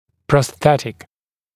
[prɔs’θetɪk][прос’сэтик]протетический, имеющий отношение к протезированию